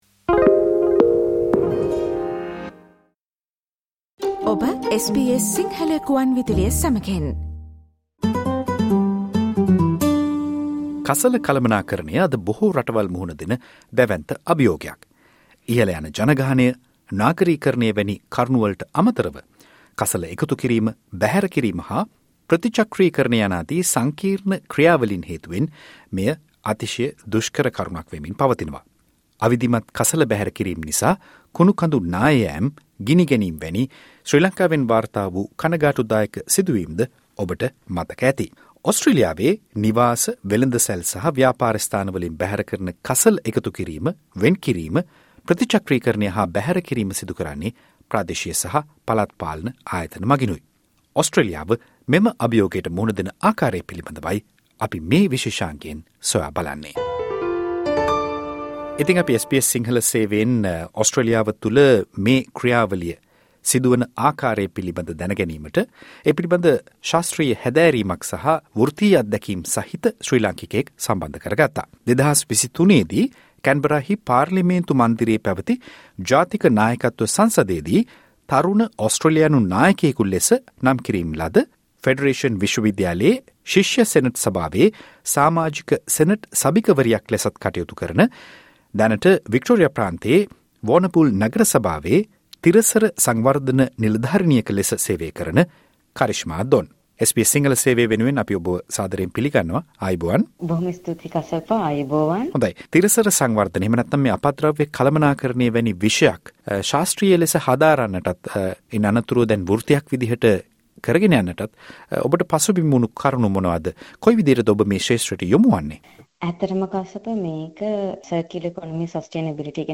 සාකච්ඡාව